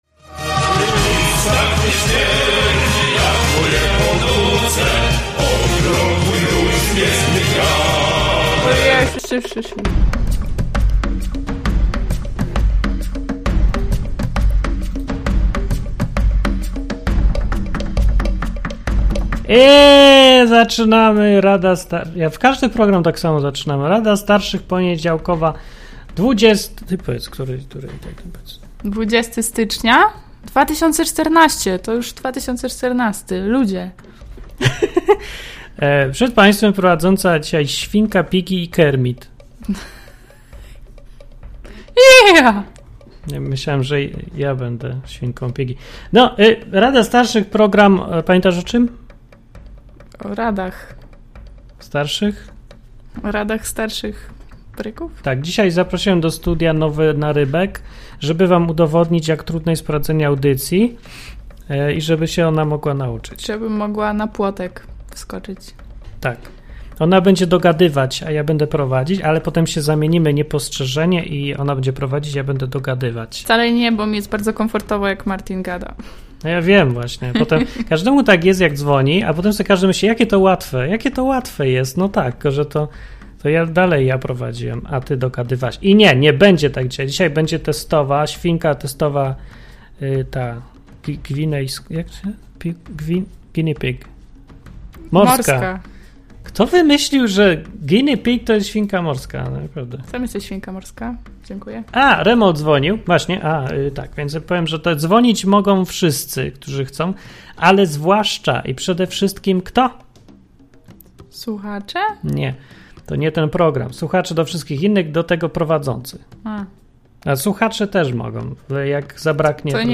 Co tydzień w poniedziałek prowadzący programy w Enklawie zbierają się, aby udzielać słuchaczom rad.